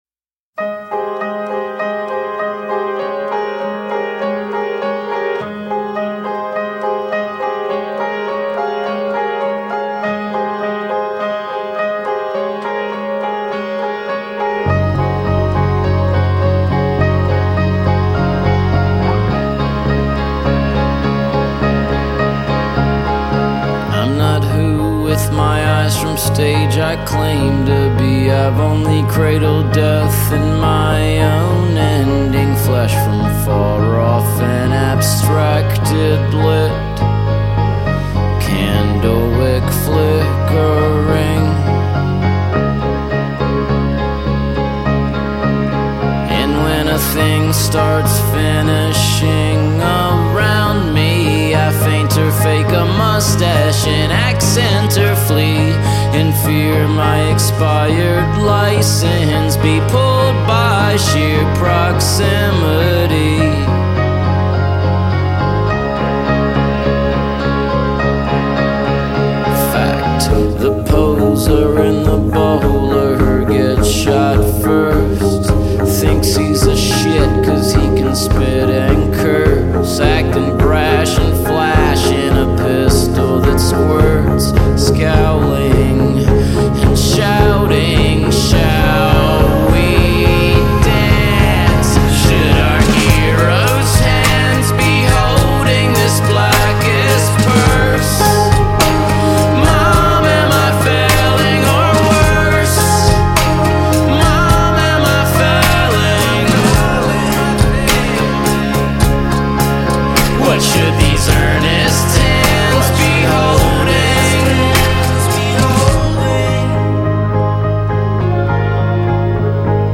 הוא תמיד עצוב ואכול בדידות